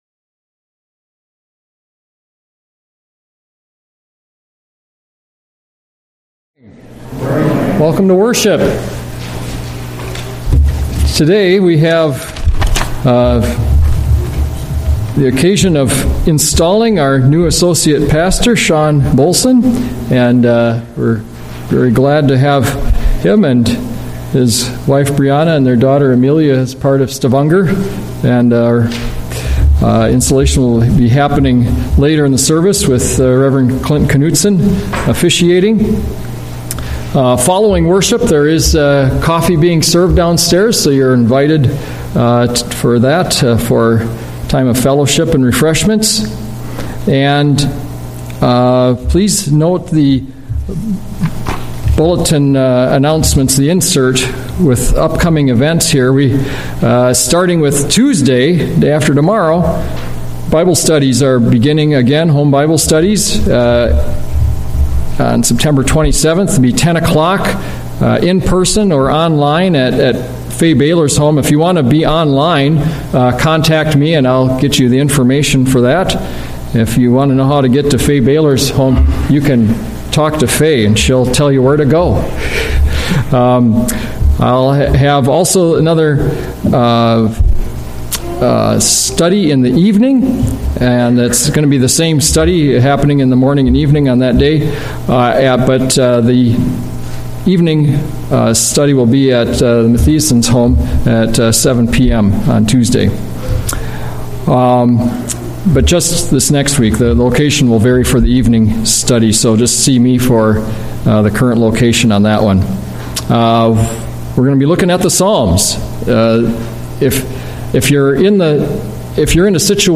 Sermons - Stavanger Lutheran Church
From Series: "Sunday Worship"